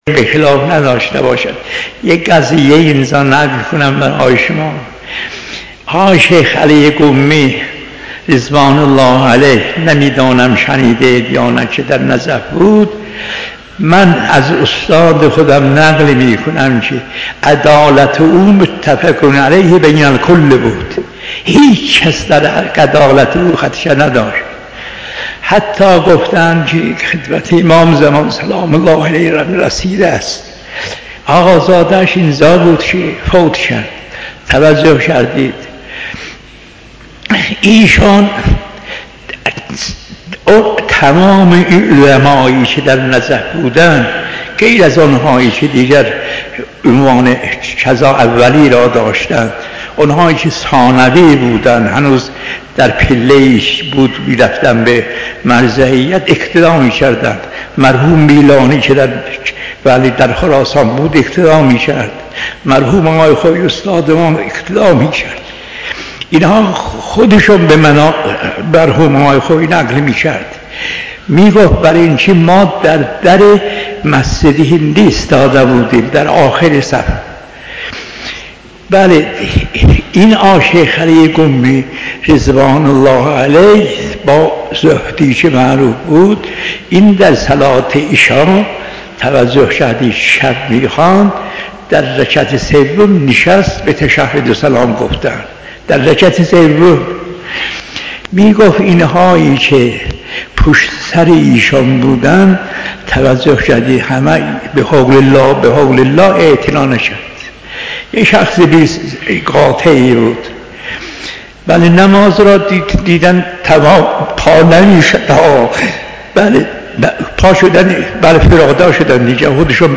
🔴بیانات فقیه بزرگوار مرحوم آیت‌الله العظمی میرزا جواد آقا تبریزی در خصوص "یقین شیخ علی زاهد قمی در نماز"